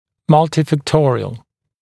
[ˌmʌltɪfæk’tɔrɪəl][ˌмалтифэк’ториэл]многофакторный